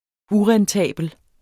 Udtale [ ˈuʁanˌtæˀbəl ]